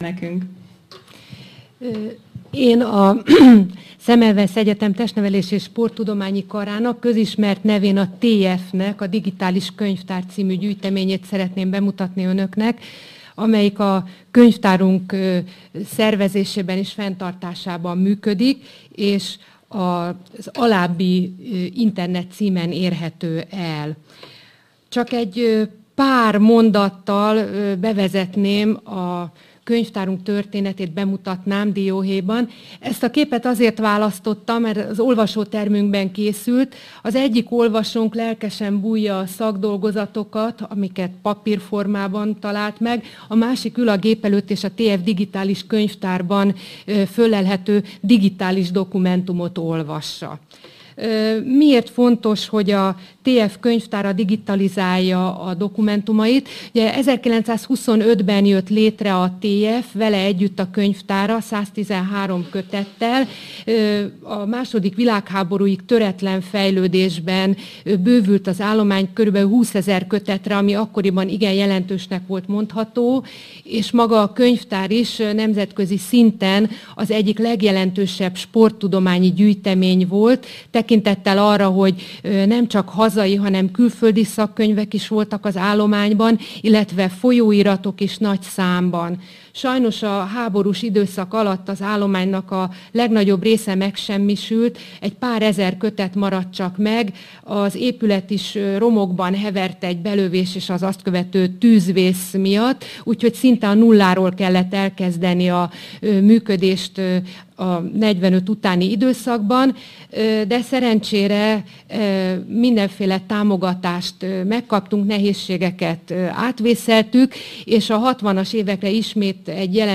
Csatornák Networkshop 2012 konferencia